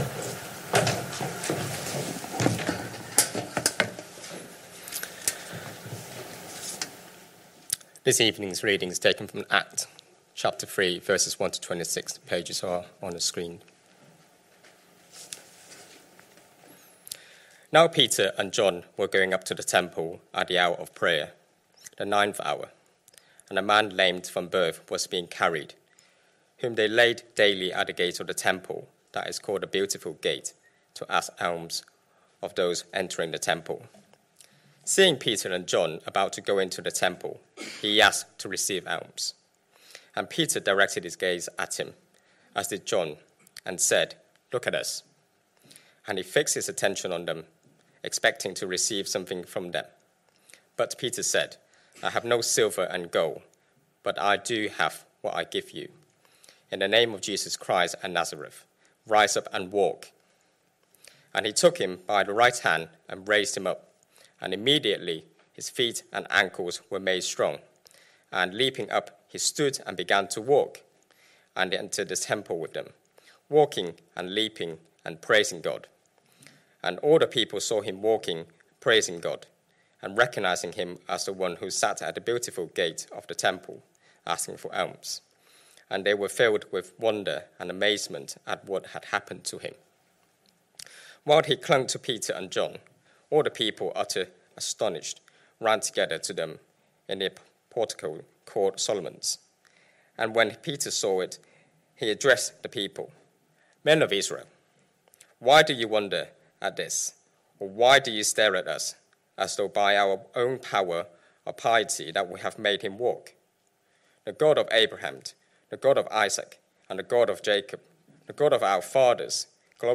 Sunday Evening Service Sunday 7th September 2025 Speaker